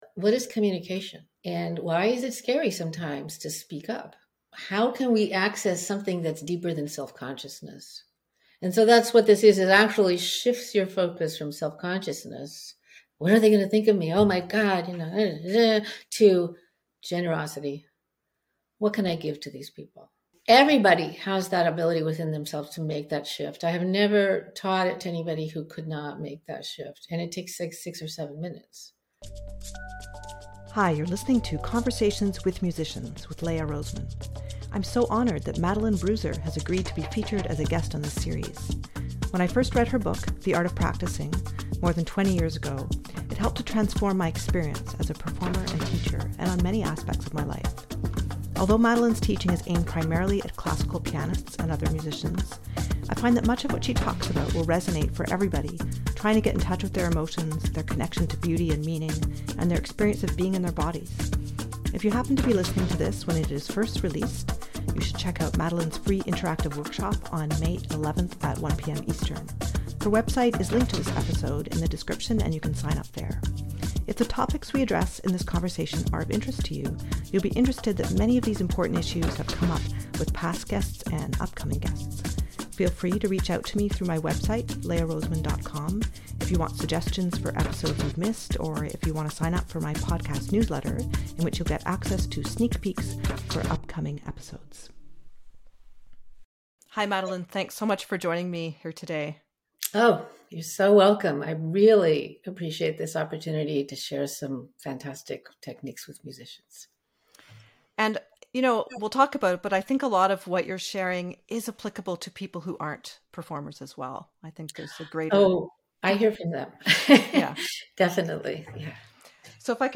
Pianist, Educator